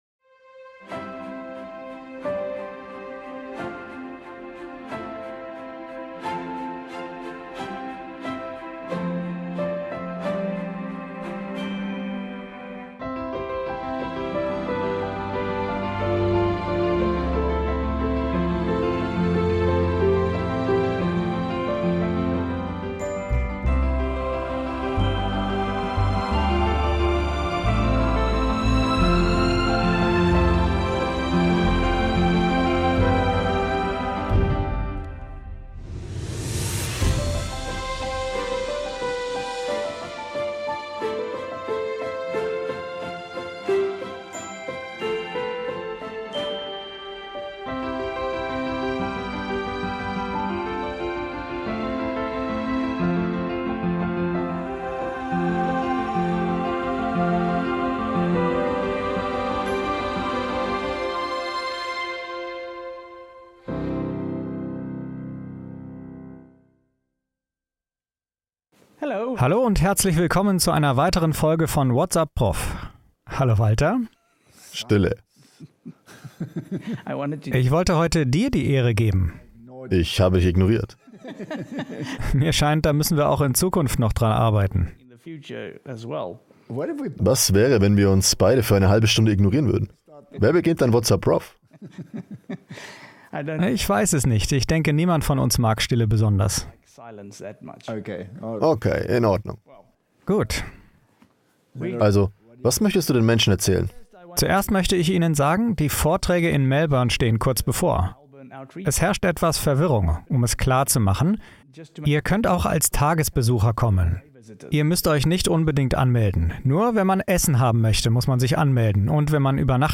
Whats Up, Prof? (Voice Over)